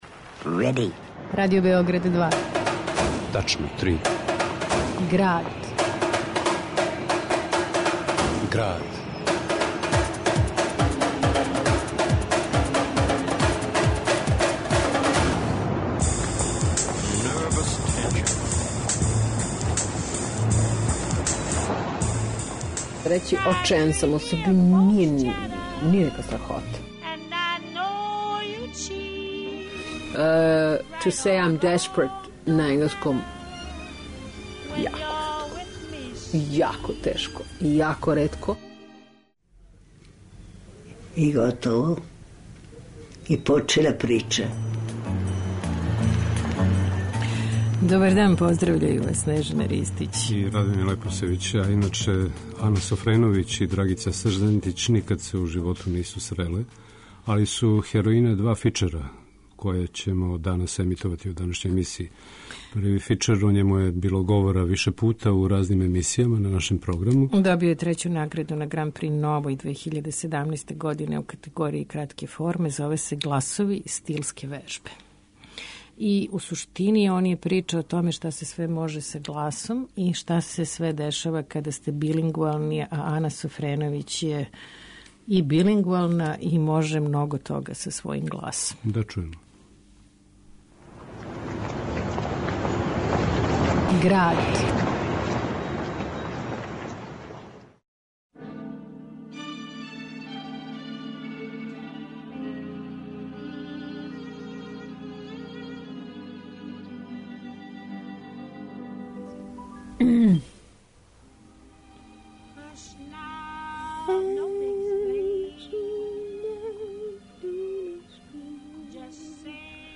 Десетоминутни радијски фичер Гласови - стилске вежбе је на фестивалу Grand Prix Nova у Букурешту освојио треће место у категорији кратке форме, а тридесетпетоминутна документарно-драмска емисија (фичер) Девет дана маја (или Октобарске трешње) тек чека своју фестивалску судбину...